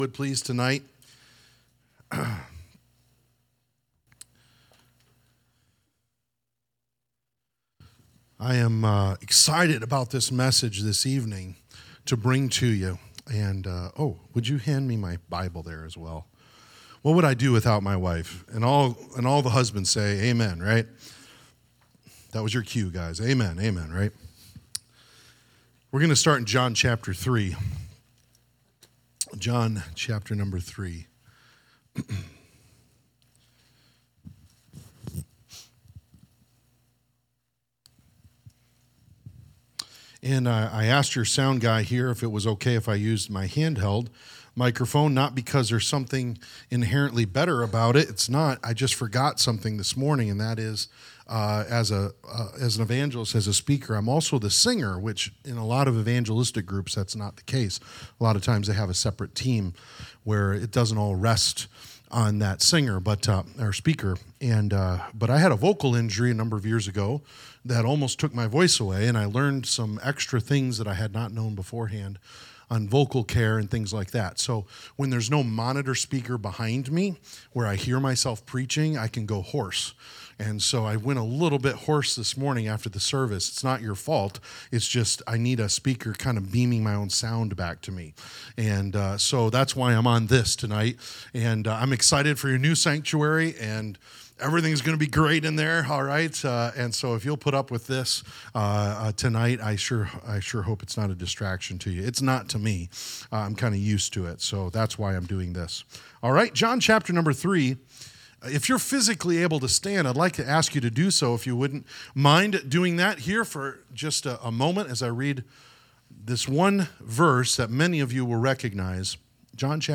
Sermons | Trinity Baptist Church